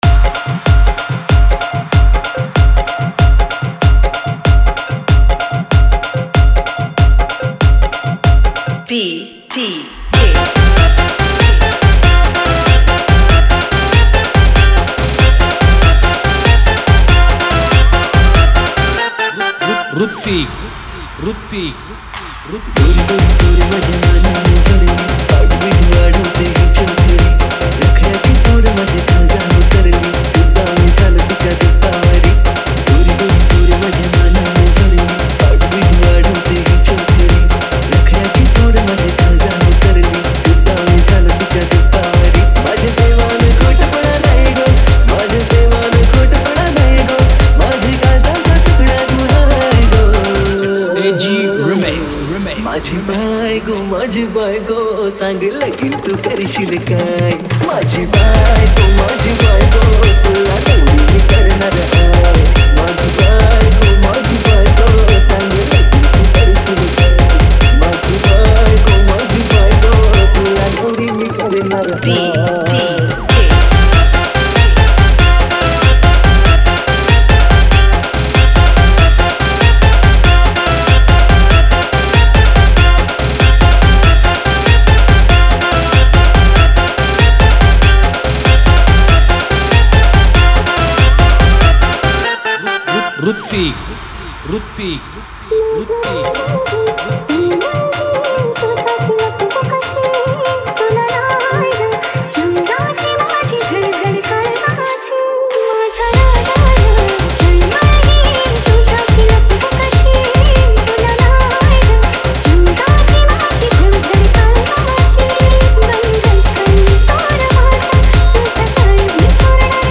Marathi Dj Single 2025